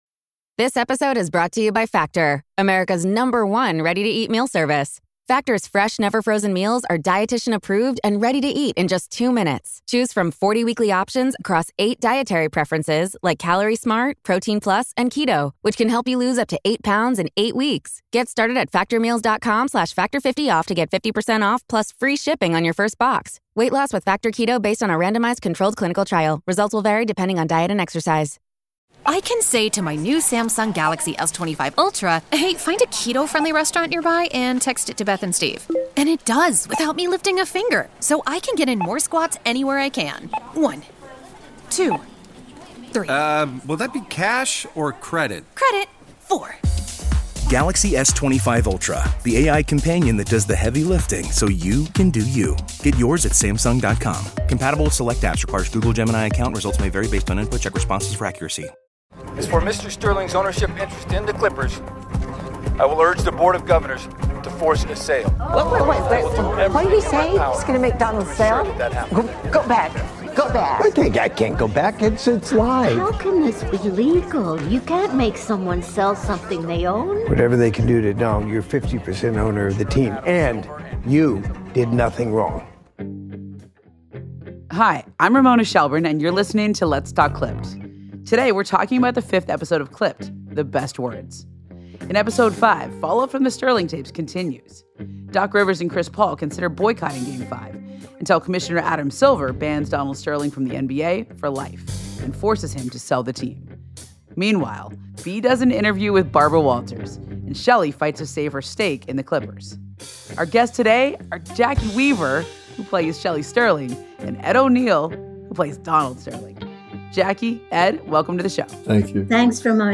Original audio documentaries from the makers of the acclaimed 30 for 30 film series, featuring stories from the world of sports and beyond. 30 for 30 offers captivating storytelling for sports fans and general interest listeners alike, going beyond the field to explore how sports, competition, athleticism and adventure affect our lives and our world.